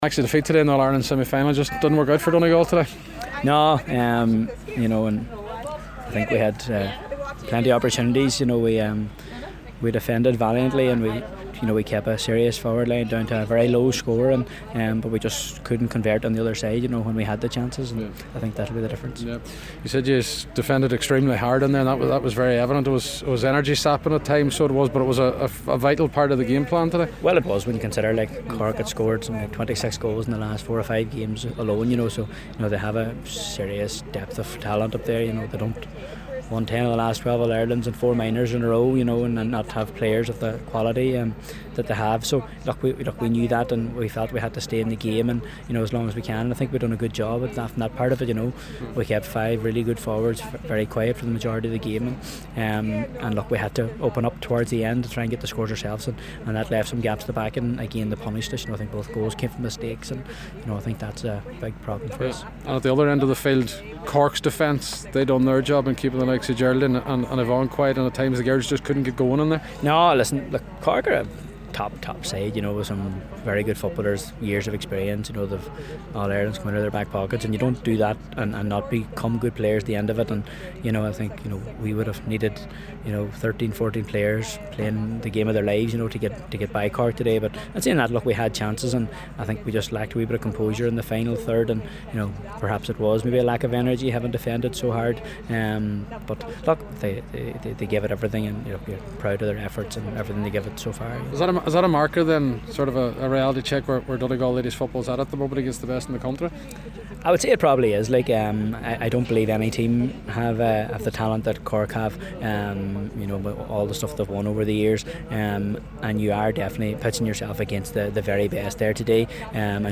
gave his thoughts on the game shortly after the final whistle